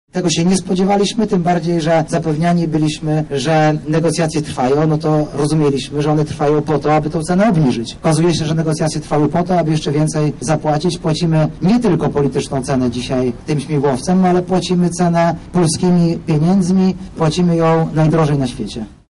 To dowód na to jak lekko wydajemy pieniądze – mówi Artur Soboń radny sejmiku wojewódzkiego z PiS.